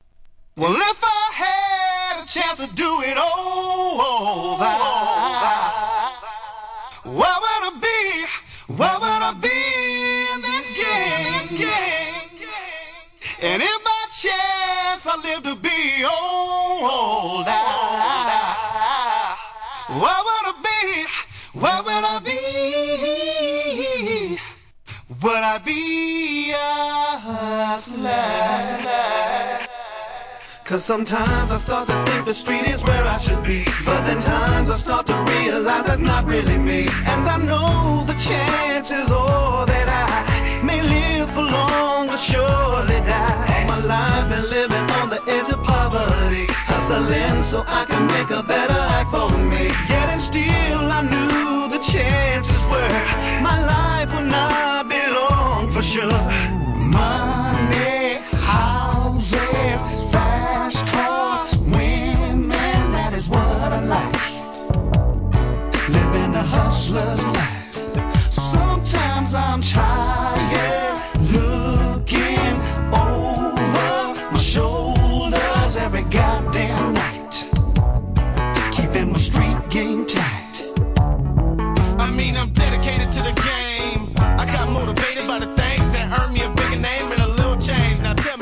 G-RAP/WEST COAST/SOUTH
激メロ〜G